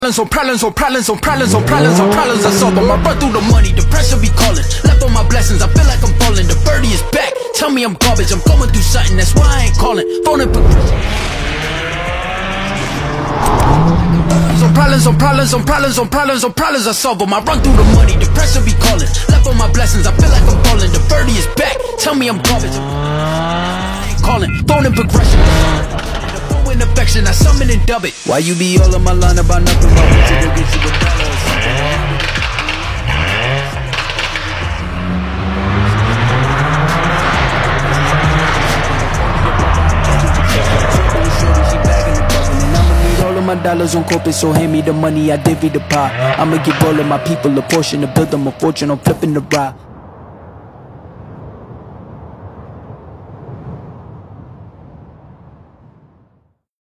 Cinematic Boosted Madness: GLA45 AMG